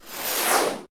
laser2.ogg